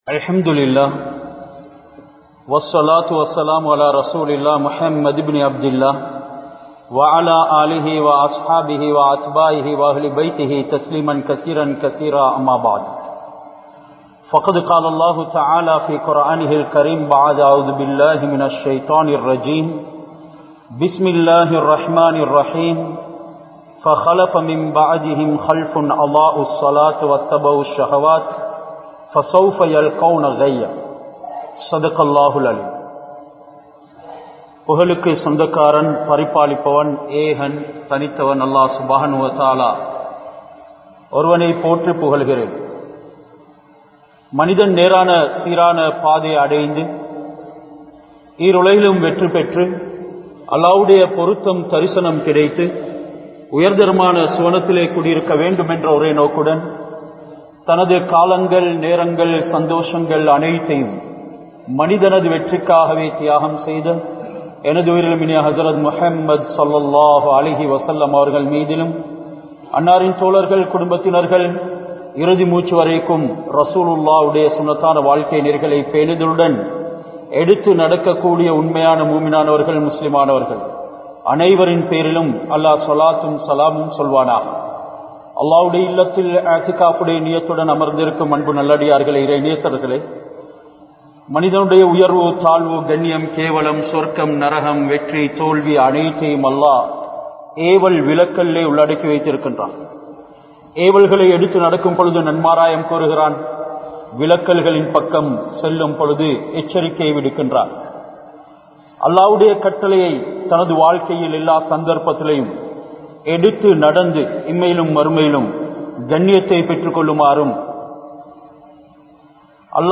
Tholuhaiel Peanuthalaaha Irungal (தொழுகையில் பேனுதலாக இருங்கள்) | Audio Bayans | All Ceylon Muslim Youth Community | Addalaichenai
Walampoda Grand Jumua Masjith